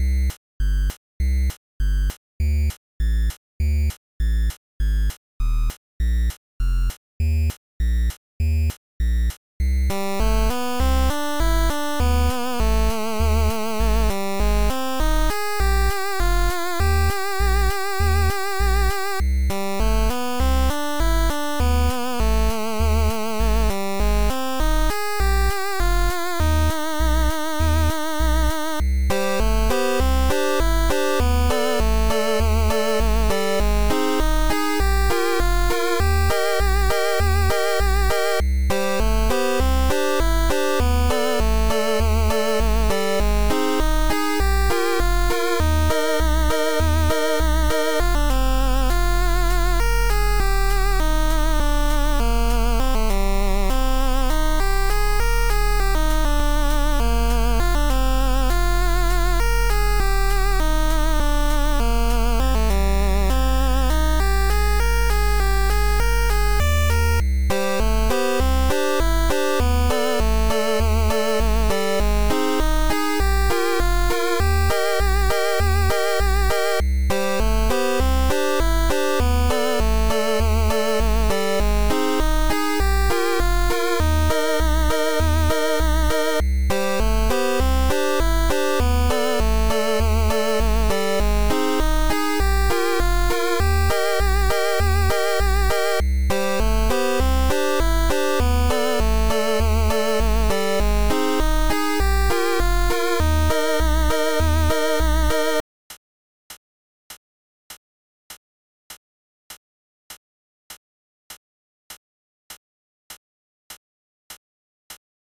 BGM track